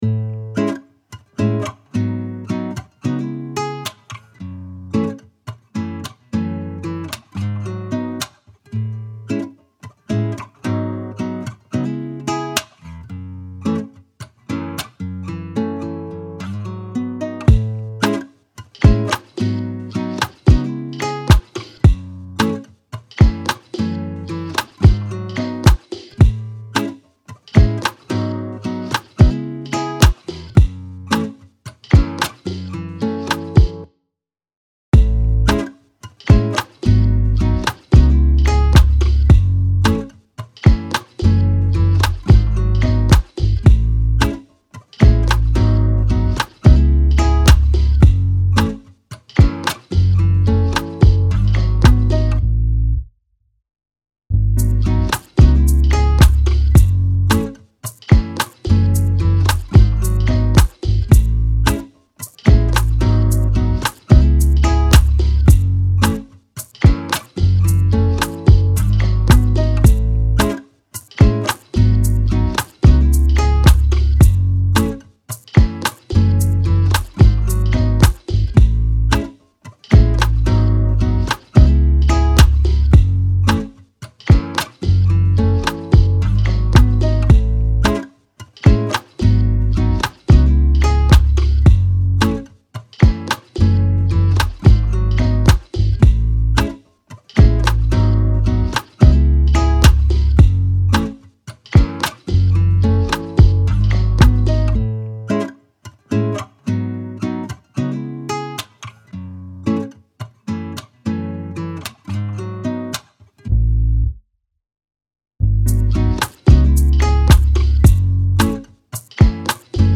R&B, Afrobeat
C# Minor